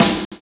Snare set 2 011.wav